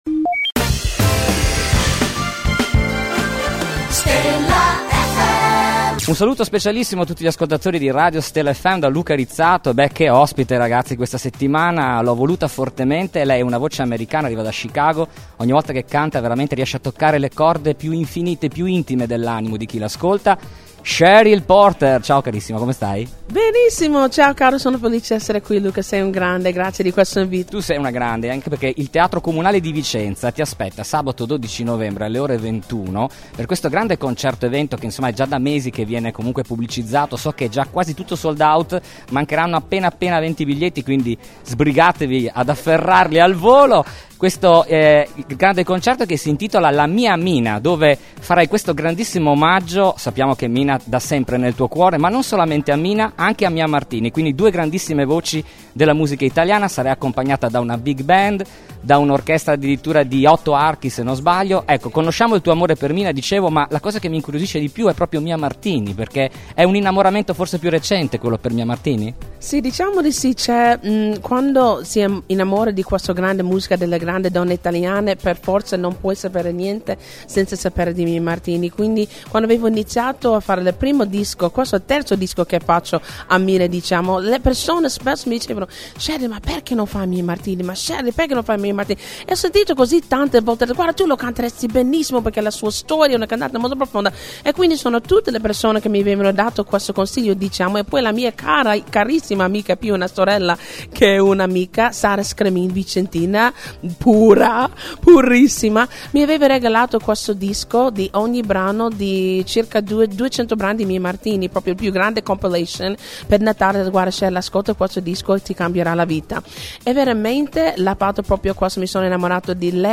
Intervista esclusiva per Stella Fm con Cheryl Porter, cantante americana dalla voce unica che riesce ad arrivare dentro l’animo di chi ascolta Le sue molteplici capacità vocali le consentono di attraversare diversi generi musicali: gospel, jazz, spirituals, classica.